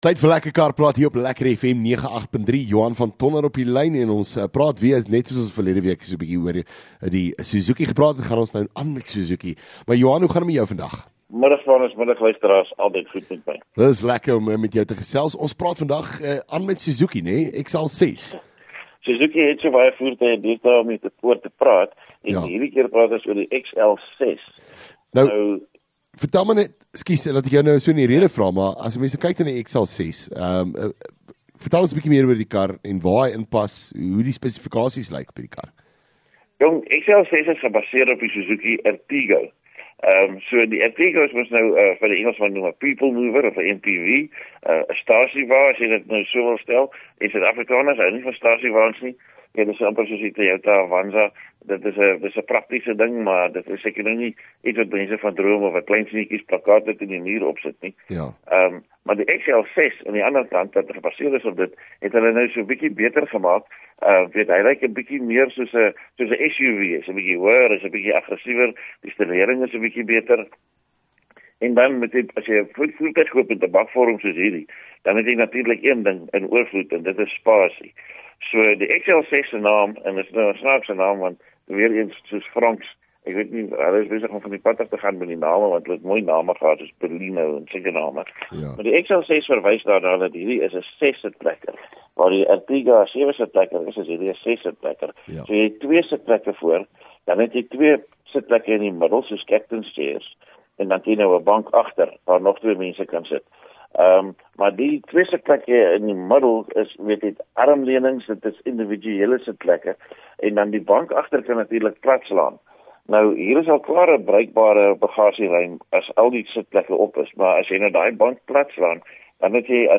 LEKKER FM | Onderhoude 1 Sep Lekker Kar Praat